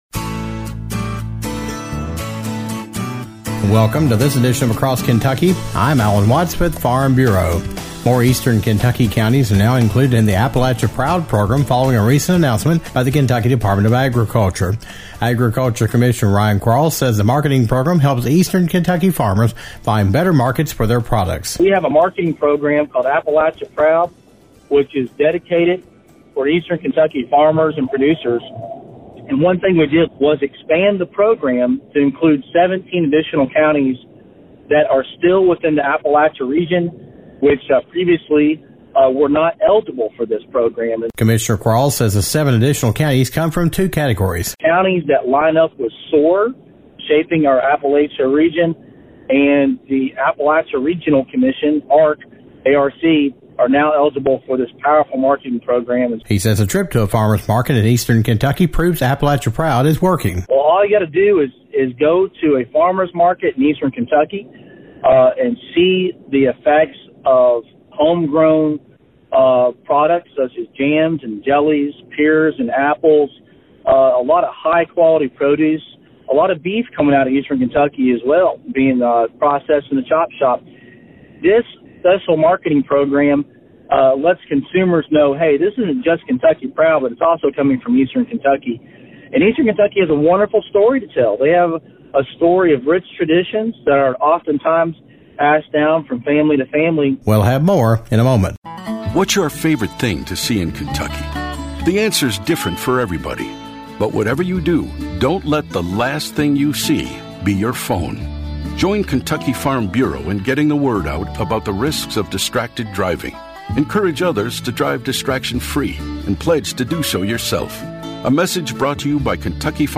The Appalachia Proud Program is expanding to include seven additional counties in Eastern Kentucky. Kentucky Agriculture Commissioner Ryan Quarles talks about the expansion and what it will mean to Eastern Kentucky farmers.